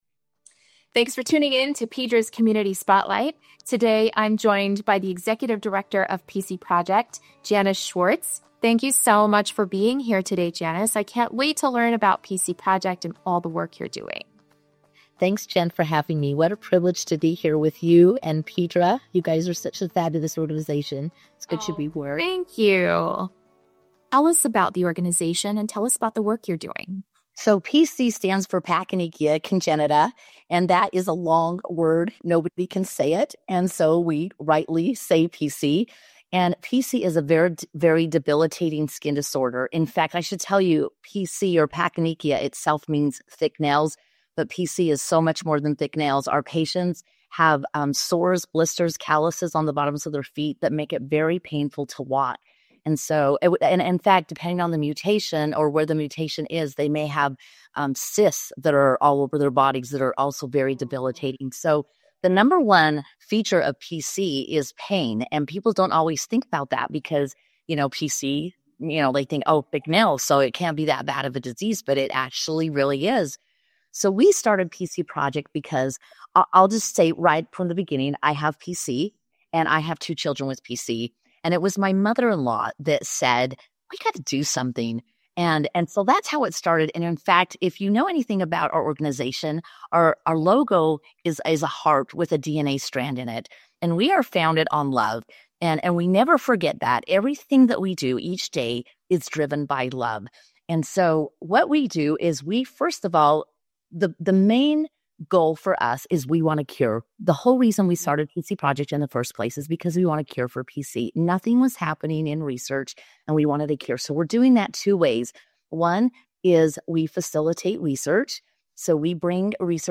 2025-Jan-Interview-converted.mp3